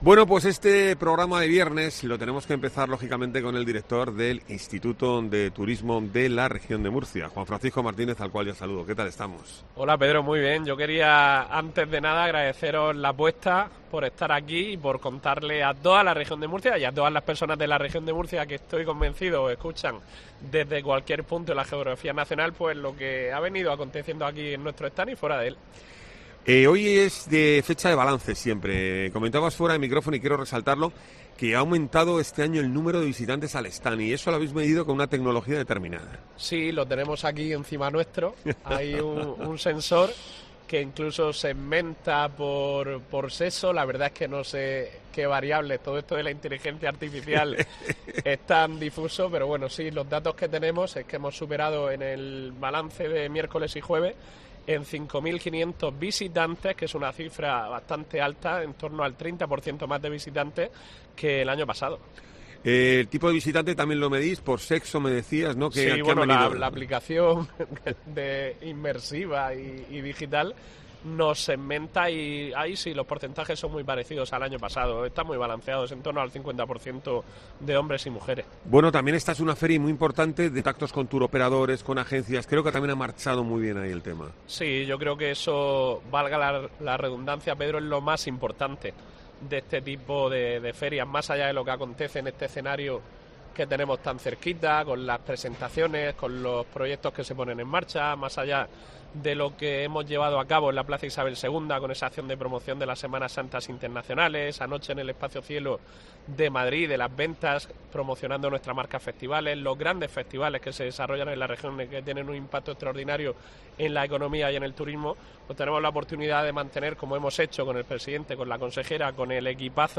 Juan Francisco Martínez, director general del ITREM, hace balance de FITUR 2024